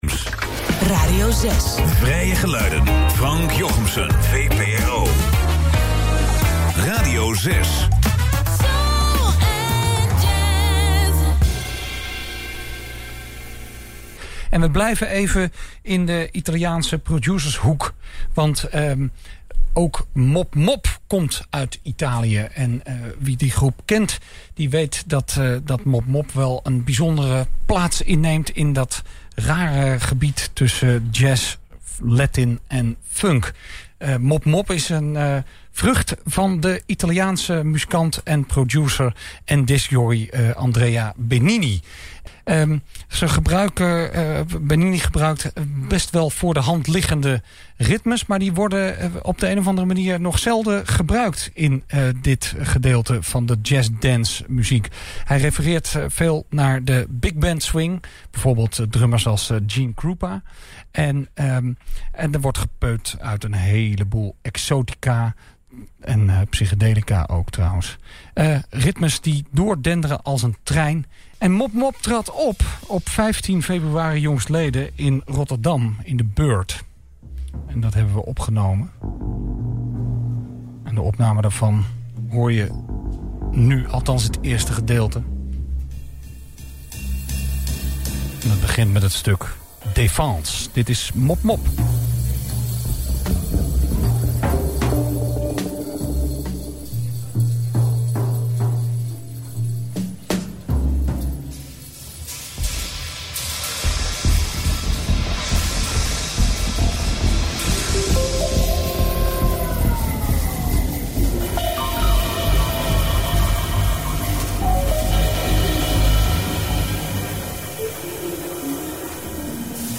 vibraphone
electric piano, clavinet
percussion
drums Ft.
vocals